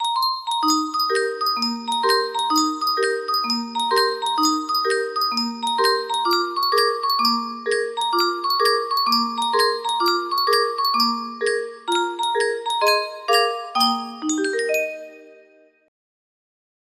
Yunsheng Music Box - Hokey Cokey 4191 music box melody
Full range 60